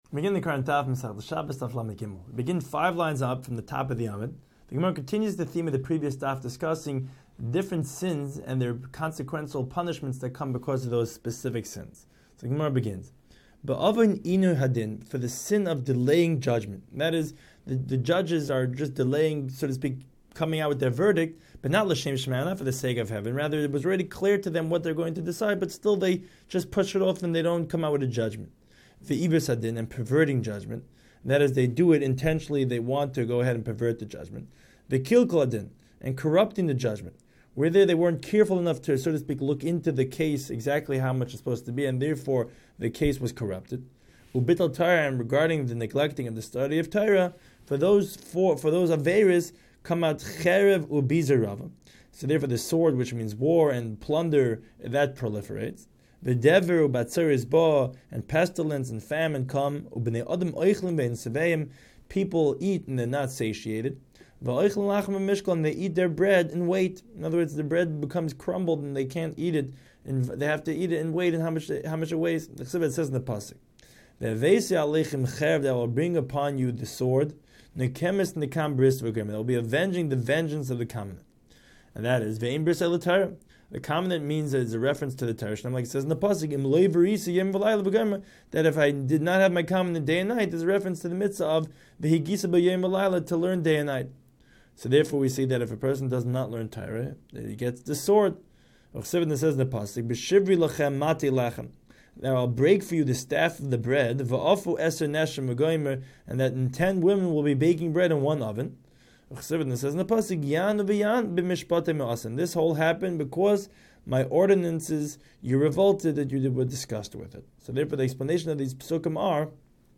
Daf Hachaim Shiur for Shabbos 33